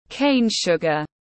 Đường mía tiếng anh gọi là cane sugar, phiên âm tiếng anh đọc là /ˈkeɪn ˌʃʊɡ.ər/
Để đọc đúng đường mía trong tiếng anh rất đơn giản, các bạn chỉ cần nghe phát âm chuẩn của từ cane sugar rồi nói theo là đọc được ngay.